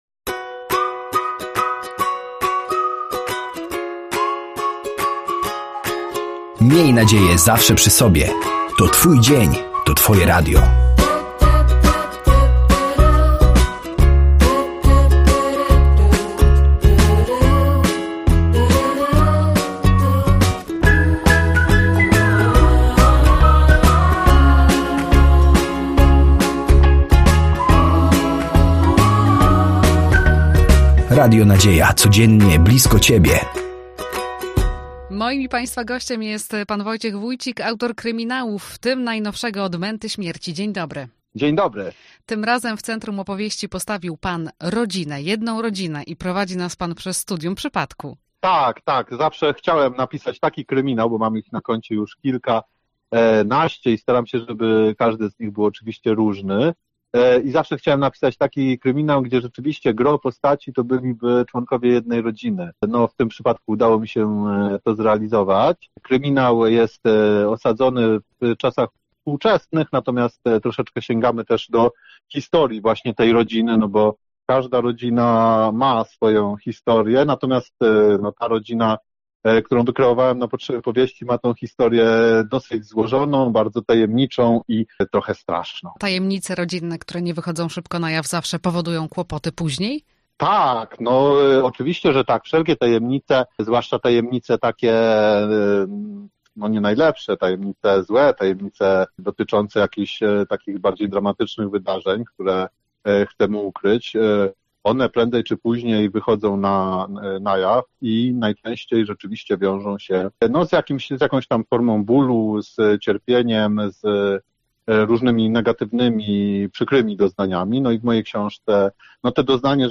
Rozmowa RN